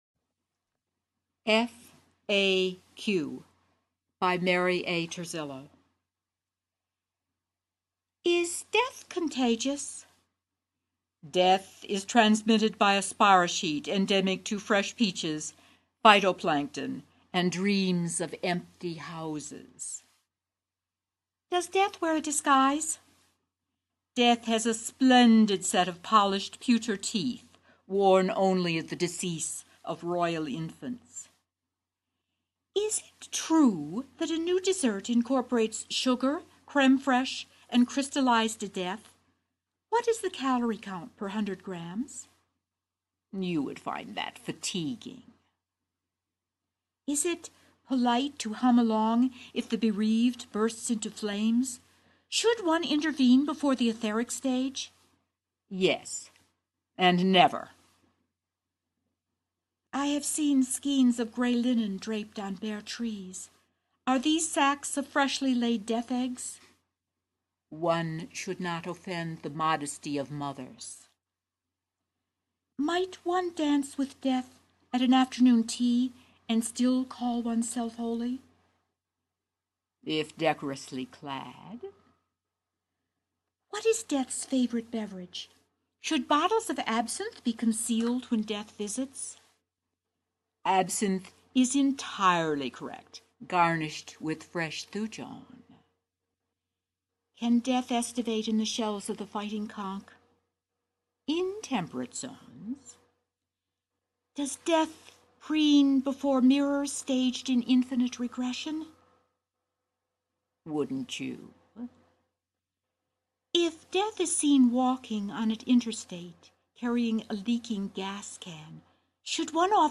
2009 Halloween Poetry Reading